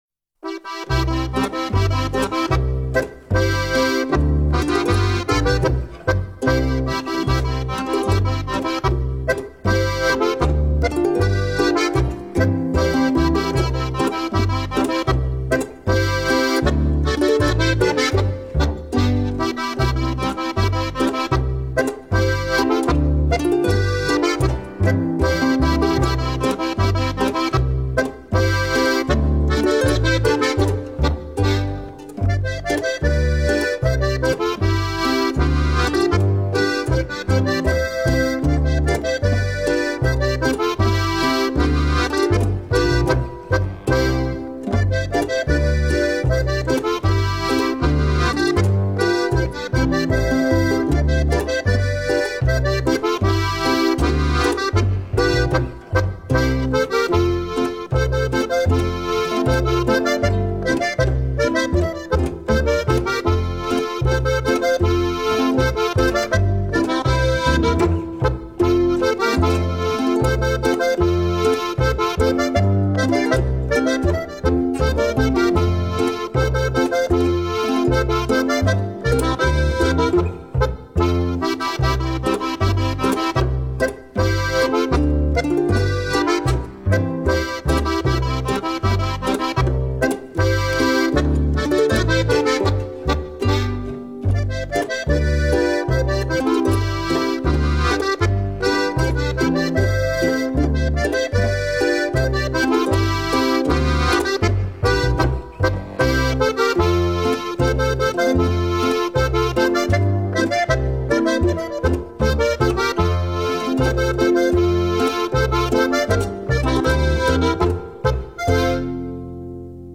Musik zum Pflichttanz: